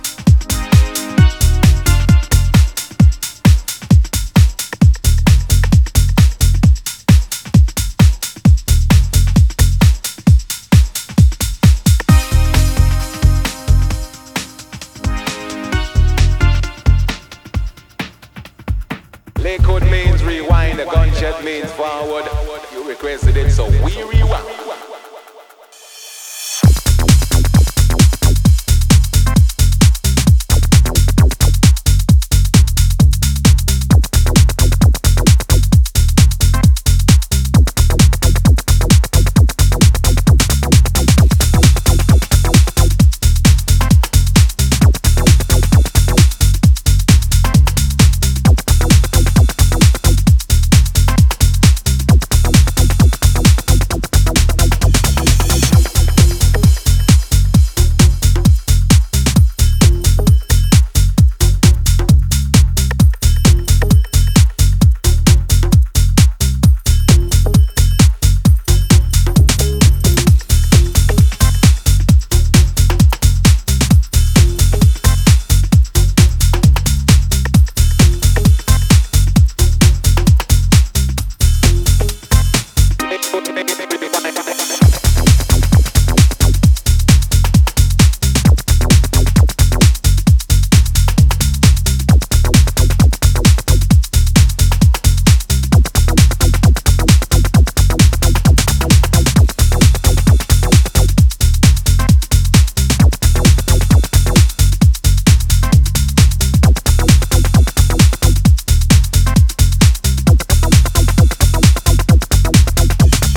UKG or breaks